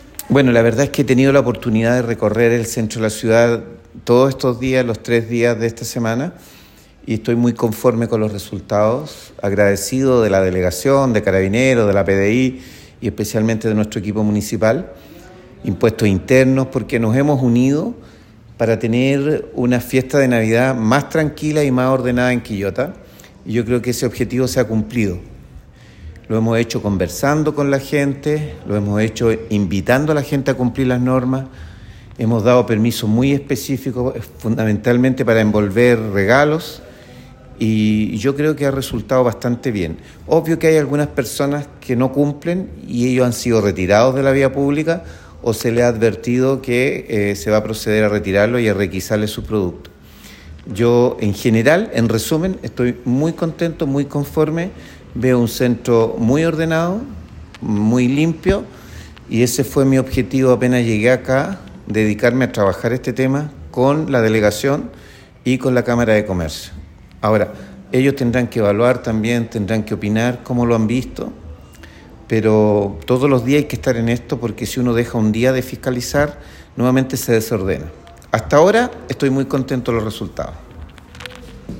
Escucha al alcalde doctor Luis Mella en este enlace: